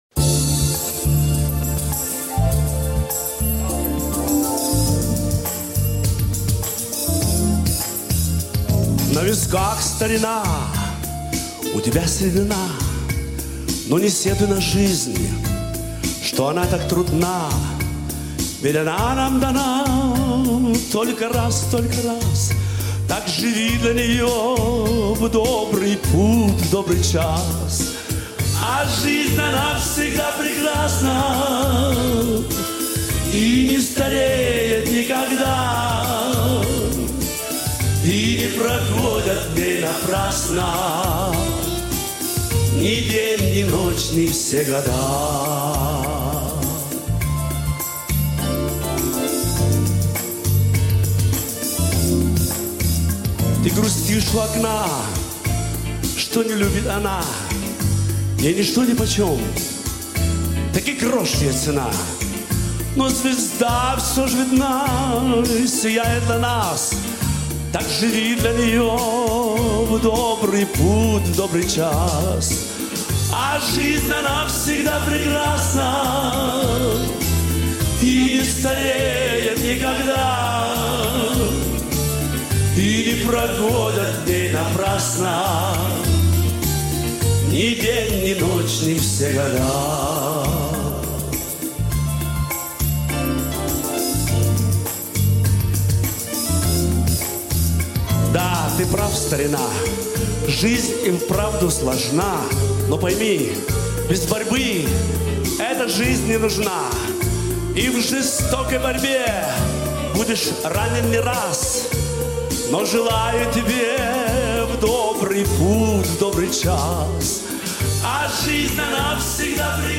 Live 2010